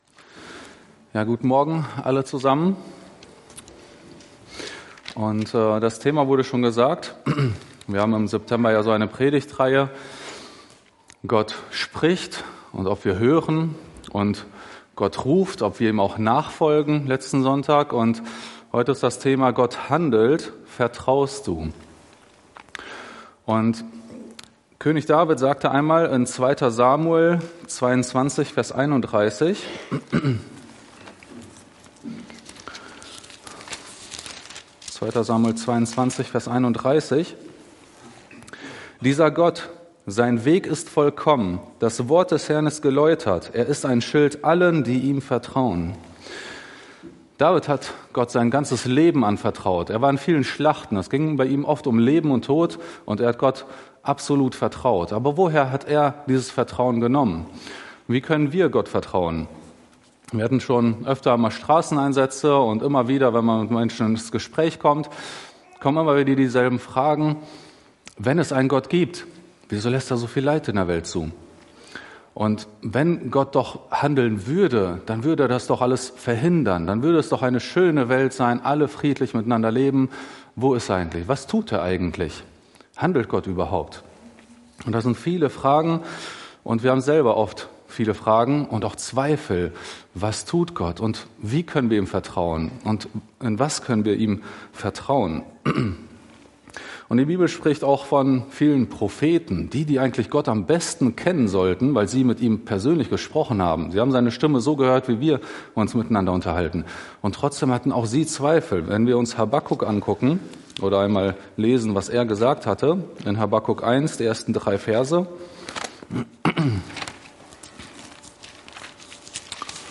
Verknüpfte Predigten